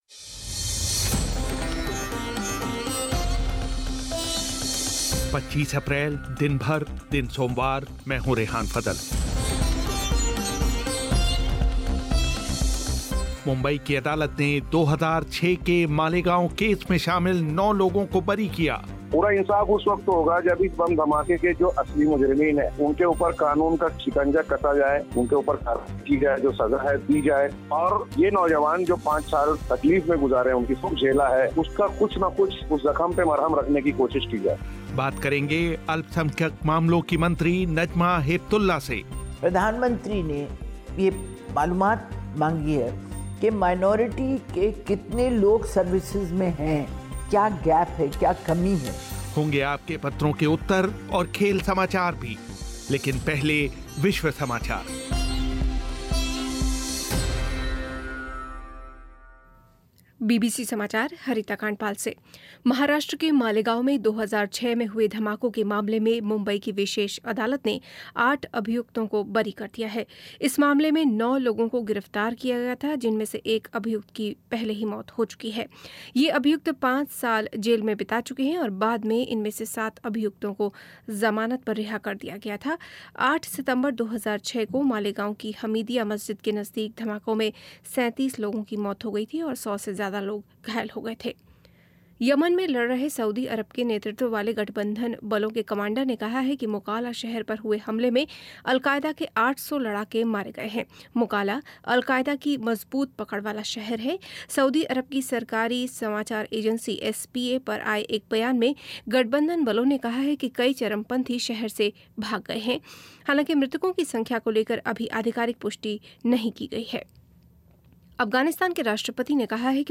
मुंबई की अदालत ने 2006 के मालेगांव ब्लास्ट केस में आरोपी सभी नौ लोगों को बरी किया. बात करेंगे अल्पसंख्यक मामलों की मंत्री नजमा हेपतुल्ला से पश्चिम बंगाल विधानसभा का चौथे चरण का मतदान संपन्न. 78 फ़ीसदी मतदान की ख़बर होंगे आपके पत्रों के उत्तर और खेल समाचार भी